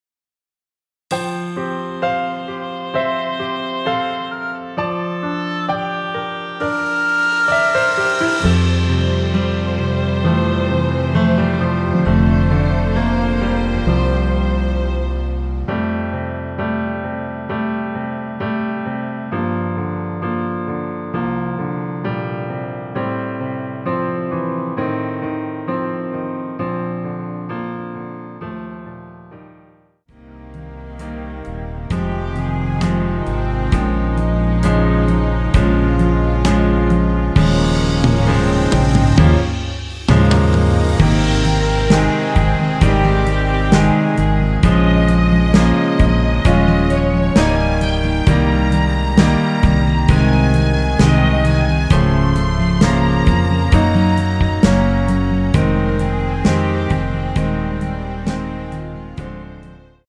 원키에서 (-5)내린 MR 입니다.
앞부분30초, 뒷부분30초씩 편집해서 올려 드리고 있습니다.
중간에 음이 끈어지고 다시 나오는 이유는